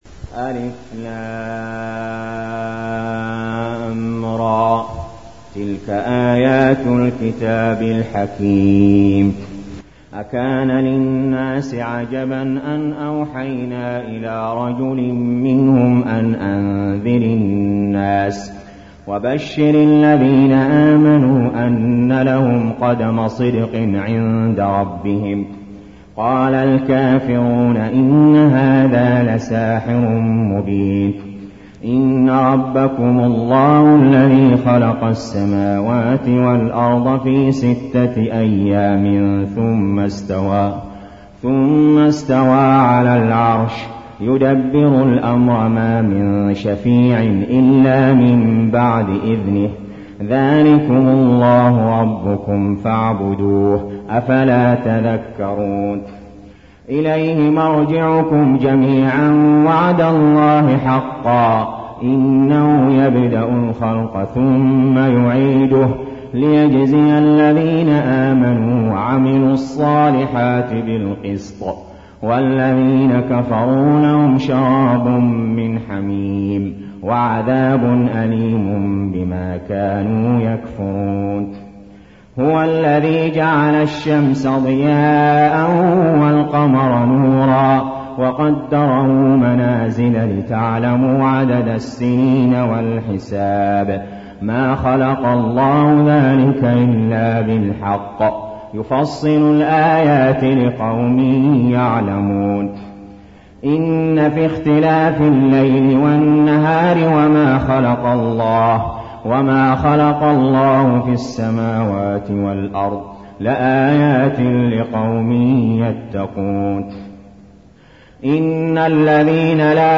المكان: المسجد الحرام الشيخ: علي جابر رحمه الله علي جابر رحمه الله يونس